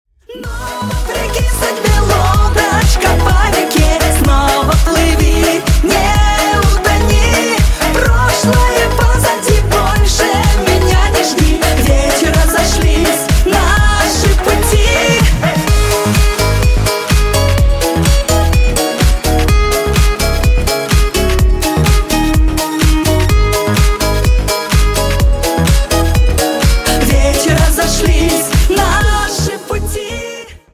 Поп Рингтоны